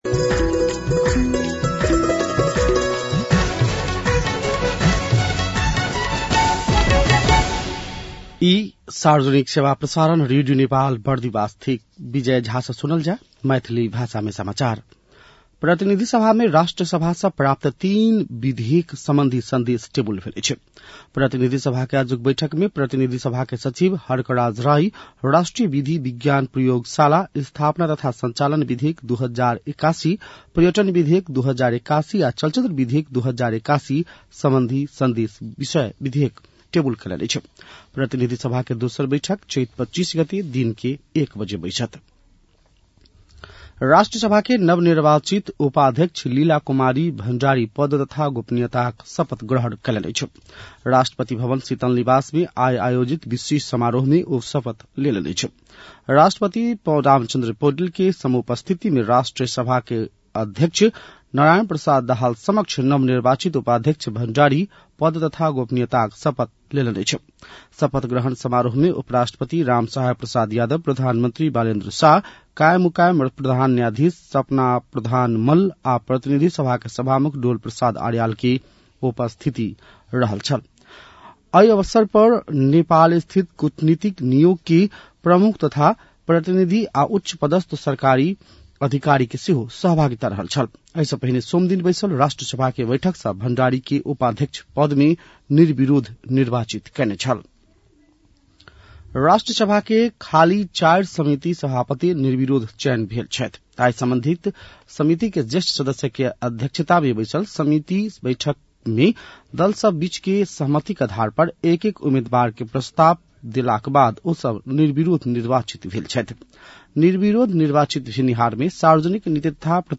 मैथिली भाषामा समाचार : २४ चैत , २०८२